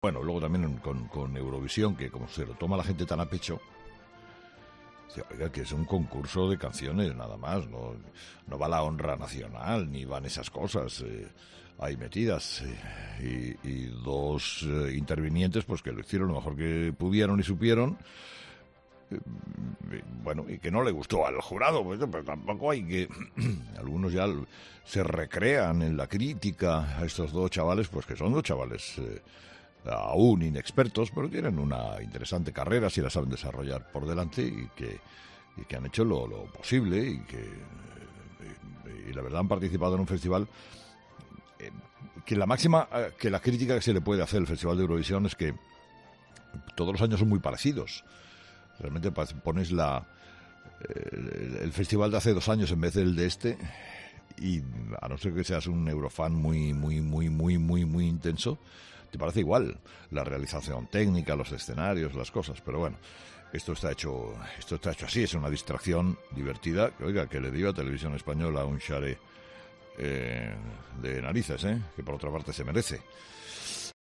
En su monólogo de hoy de las 06.30h., Carlos Herrera ha opinado sobre el concurso y sobre el vigésimo tercer puesto que obtuvieron Amaia y Alfred representando a España con "Tu canción".